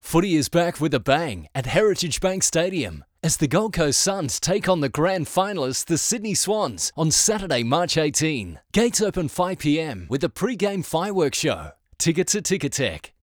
Award-winning Australian Voice. versatile, clear, and seriously experienced.
• Hard Sell
• Professional Voice booth – acoustically treated.